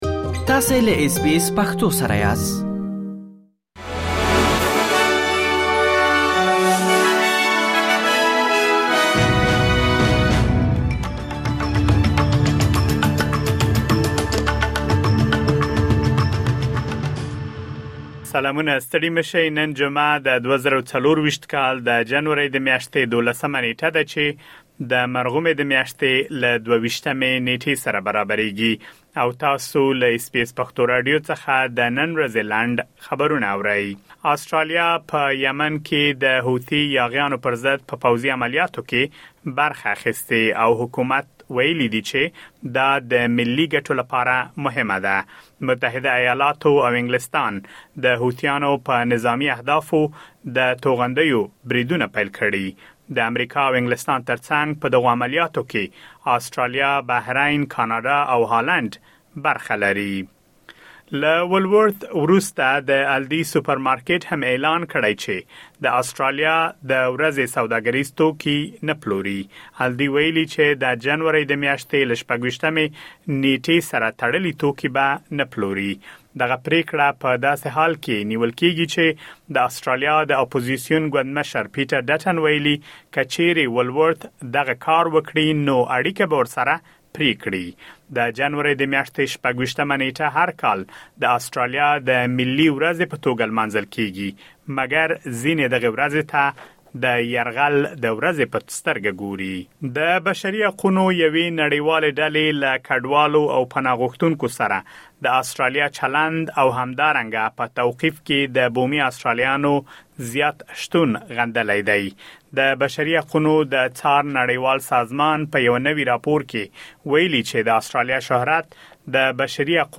د اس بي اس پښتو راډیو د نن ورځې لنډ خبرونه | ۱۲ جنوري ۲۰۲۴